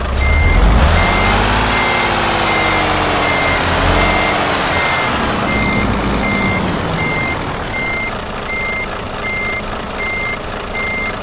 Index of /server/sound/vehicles/lwcars/truck_daf_xfeuro6
rev.wav